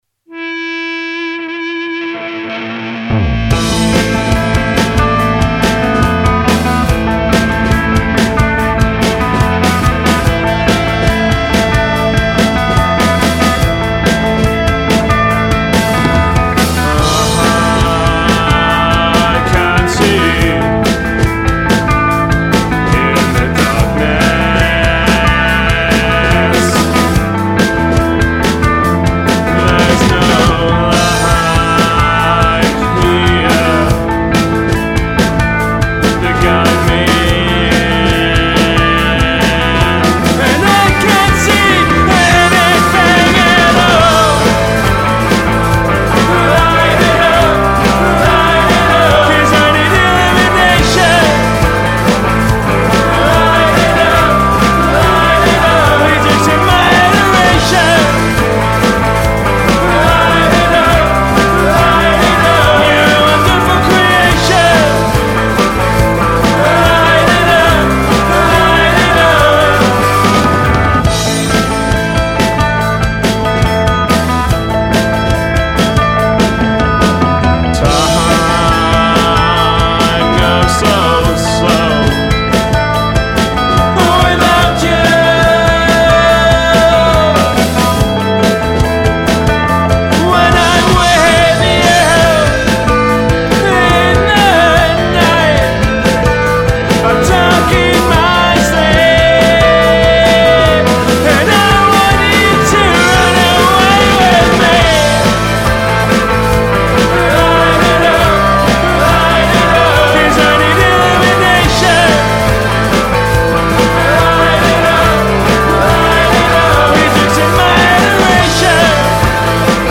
Indie rock band